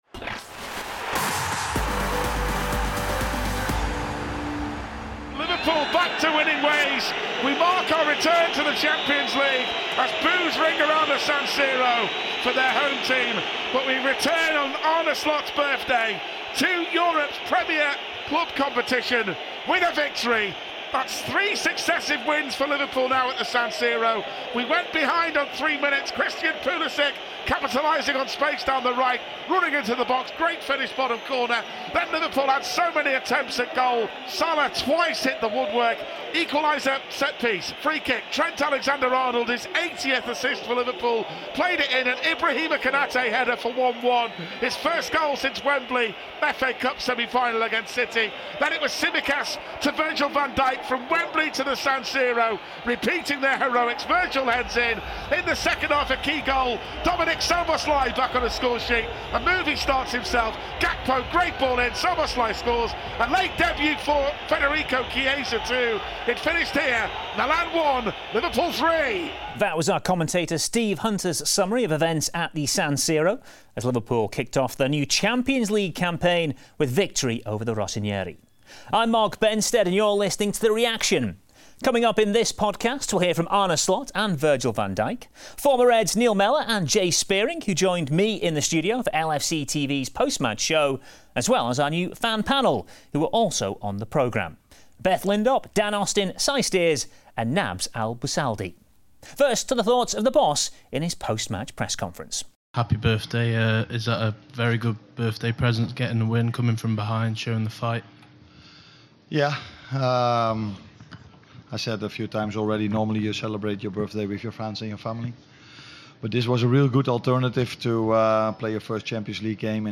Arne Slot and Virgil van Dijk reflect on Liverpool’s 3-1 win over AC Milan in their opening Champions League fixture of the season. Goals from the captain, Ibrahima Konaté and Dominik Szoboszlai saw the Reds come from behind to pick up all three points at San Siro.